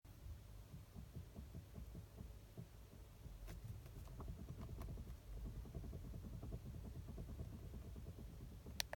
In meinem Video ist der Motor ist aus, die Zündung ist an und ich wackle mit meinem kleinen Finger.